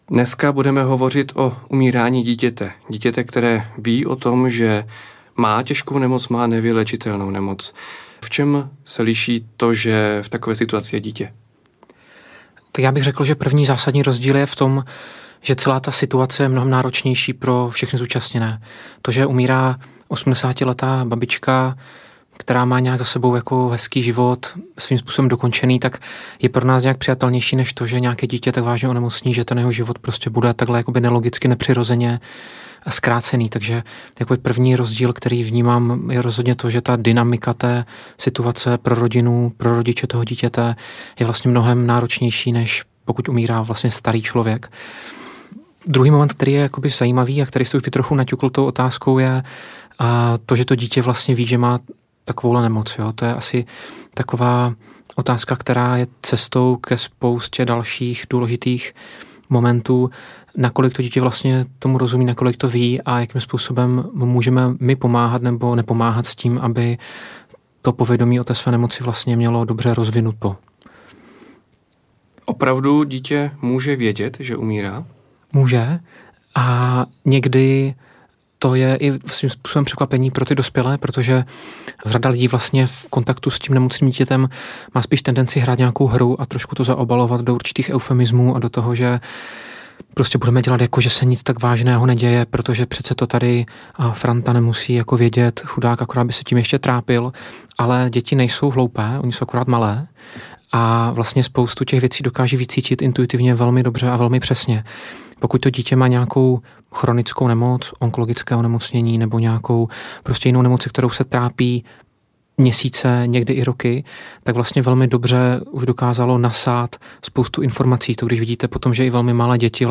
Dobrá smrt audiokniha
Ukázka z knihy